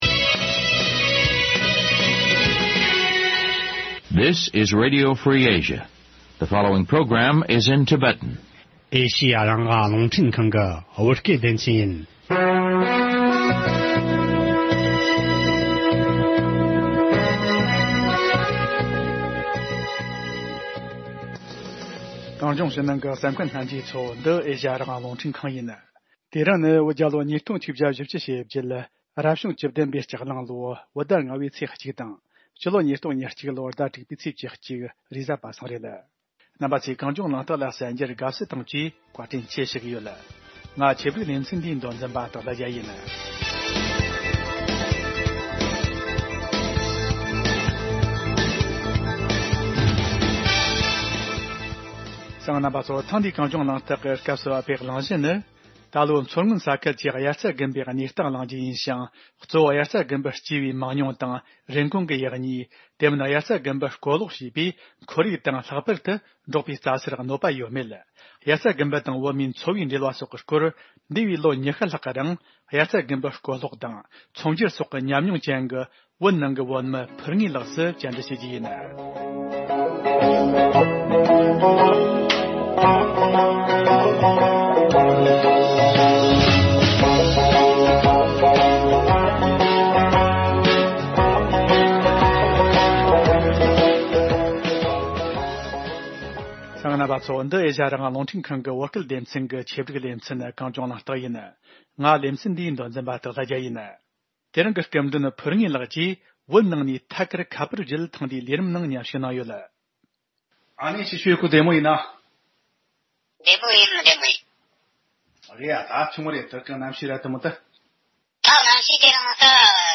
དབྱར་རྩ་དགུན་འབུ་དང་བོད་མིའི་འཚོ་བ་དང་འབྲེལ་བའི་སྐོར་དམིགས་བསལ་གླེང་མོལ་ཞུས་པ།